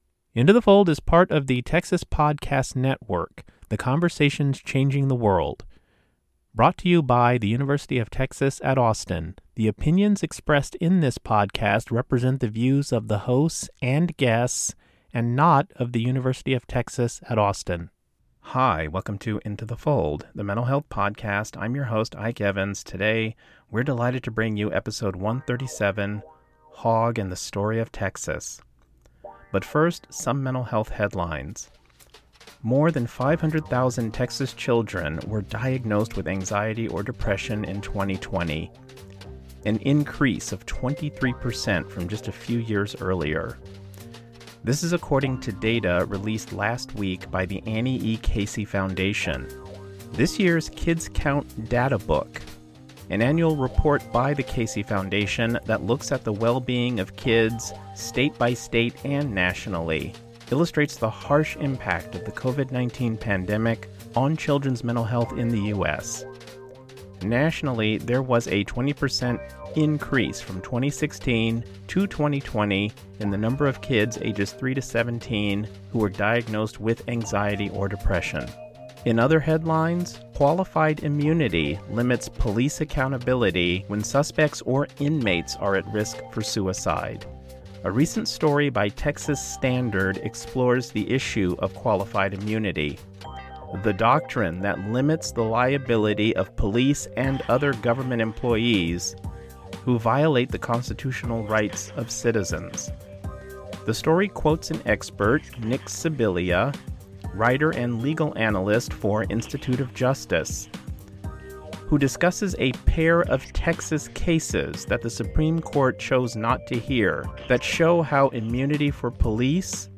In the summer of 2022 the Hogg Foundation teamed up with the Bullock Museum of Texas to contribute to their summer of programming on mental health.